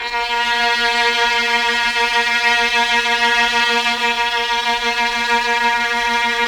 Index of /90_sSampleCDs/Keyboards of The 60's and 70's - CD1/KEY_Chamberlin/STR_Chambrln Str
STR_Chb StrA#4-L.wav